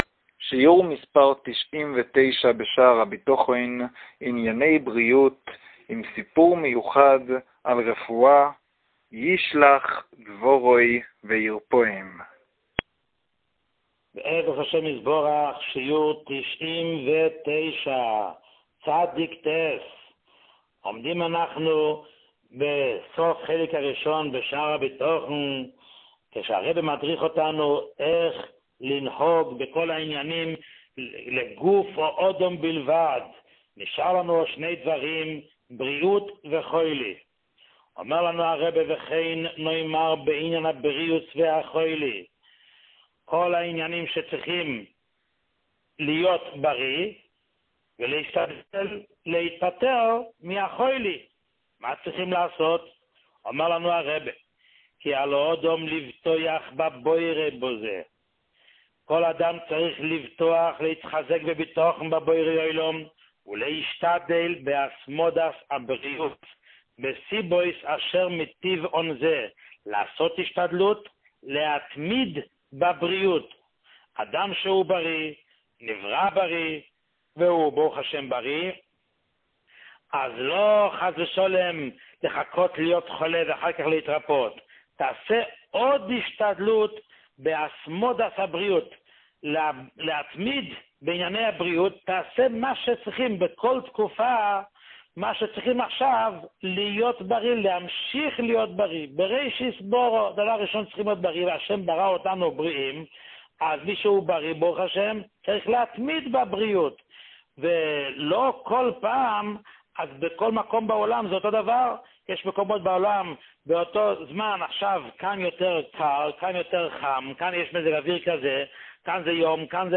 שיעור 99